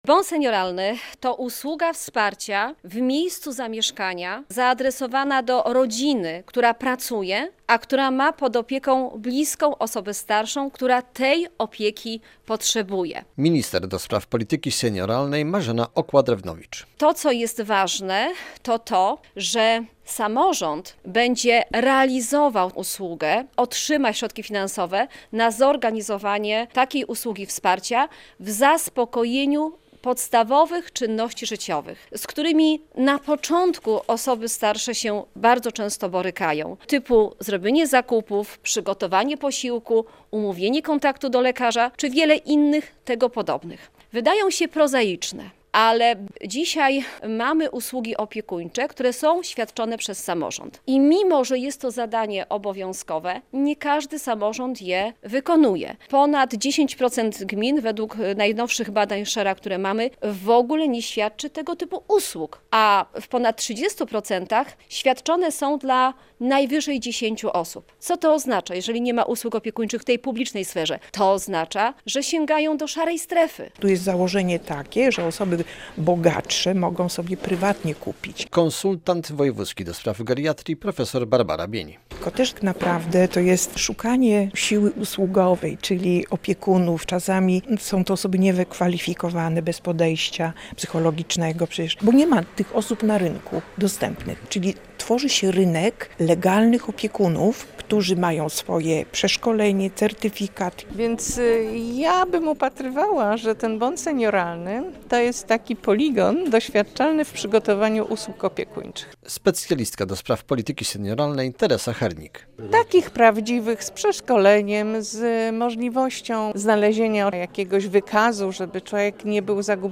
Trwają konsultacje projektu Ustawy o Bonie Senioralnym - relacja